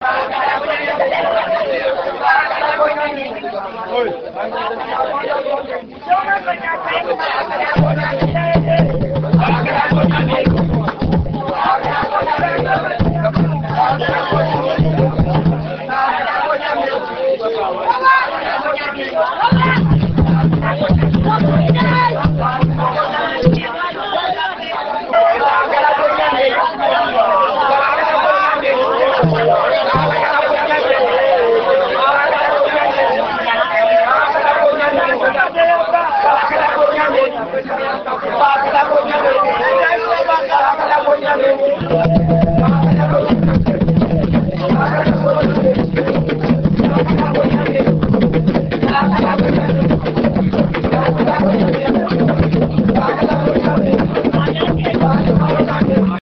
enregistrement durant une levée de deuil (Puubaaka)
danse : awassa (aluku)
Genre songe
Pièce musicale inédite